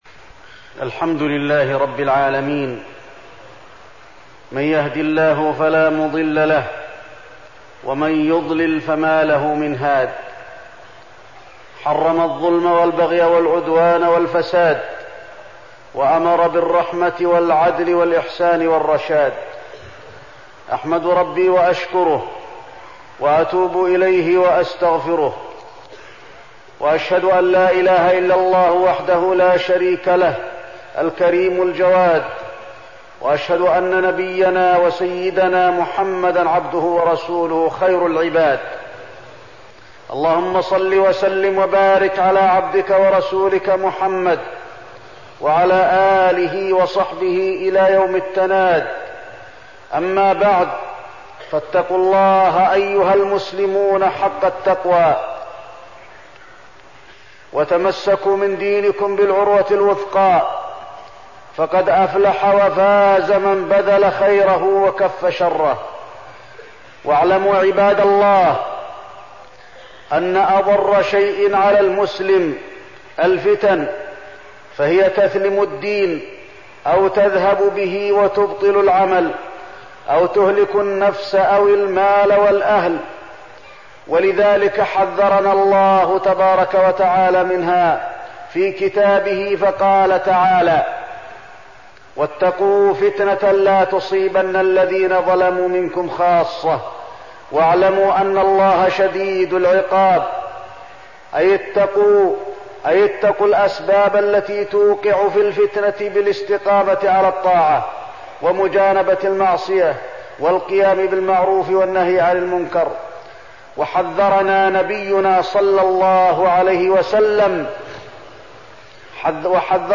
تاريخ النشر ١٢ صفر ١٤١٧ هـ المكان: المسجد النبوي الشيخ: فضيلة الشيخ د. علي بن عبدالرحمن الحذيفي فضيلة الشيخ د. علي بن عبدالرحمن الحذيفي الفتنة للمؤمن والكافر